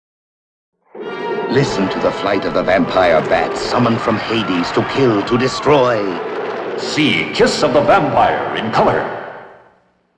Radio Spots
The radio spots presented here are atmospheric, and capture the thrills in store for the theatergoer.